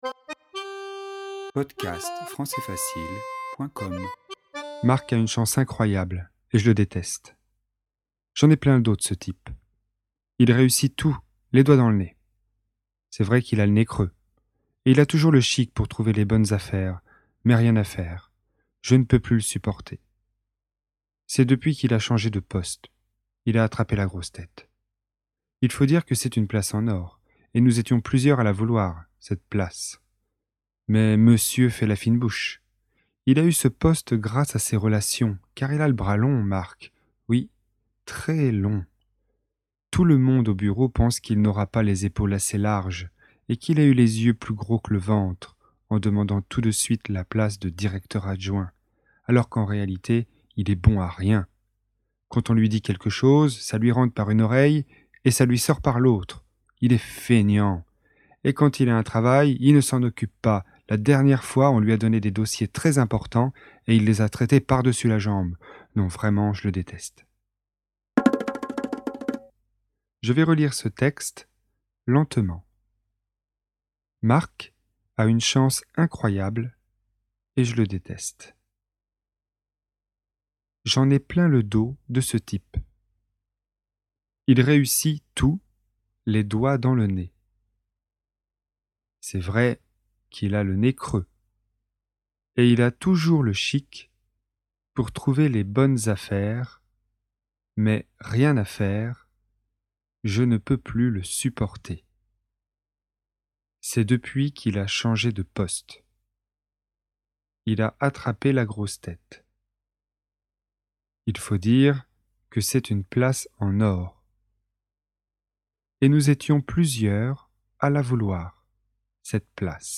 Texte FLE, niveau intermédiaire (B1), sur le thème des expressions idiomatiques.
Un employé parle d'un collègue qu'il ne supporte pas. Il utilise de nombreuses expressions idiomatiques basées sur le vocabulaire du corps humain.